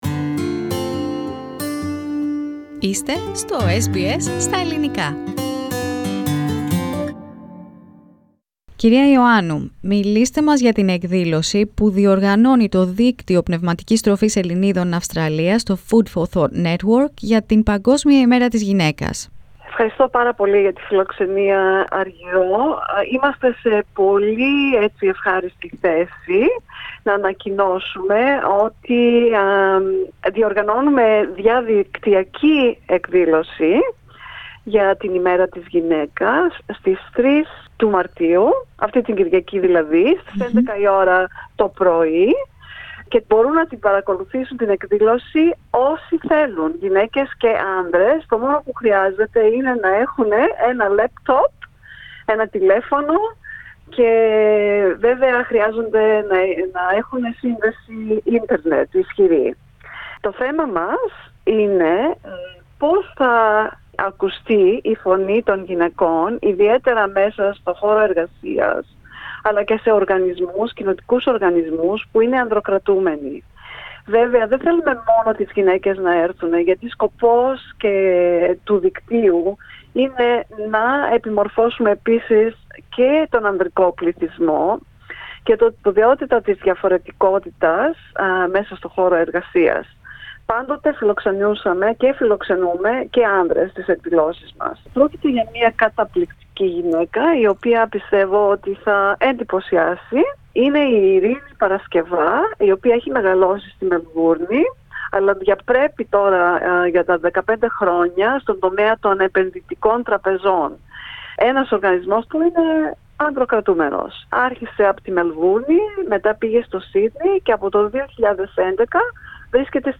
Ακούστε ολόκληρη τη συνέντευξη πατώντας Play στο Podcast που συνοδεύει την κεντρική φωτογραφία.